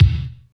29.02 KICK.wav